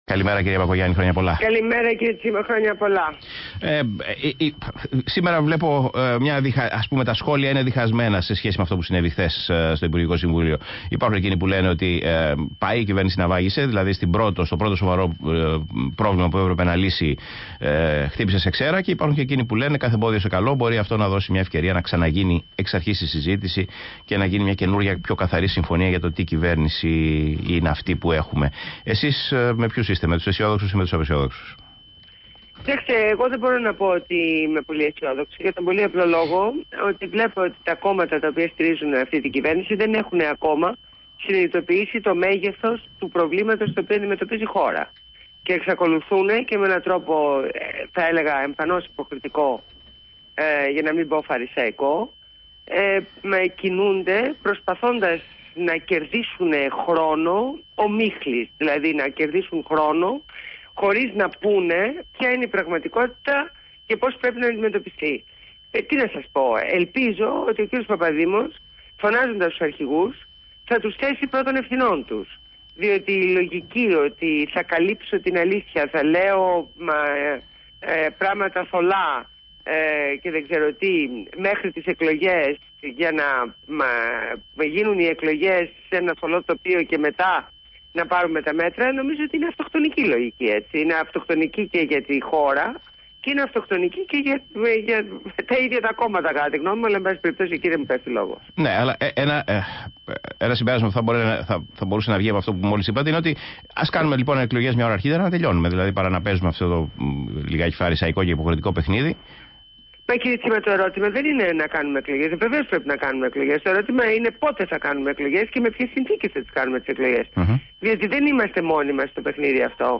Συνέντευξη Ντόρας Μπακογιάννη στο ραδιόφωνο ΒΗΜΑ fm 99.5 | Πρόεδρος
Ακούστε τη συνέντευξη της προέδρου της Δημοκρατικής Συμμαχίας στο ραδιόφωνο ΒΗΜΑ fm 99.5 και στον Παύλο Τσίμα (22/12).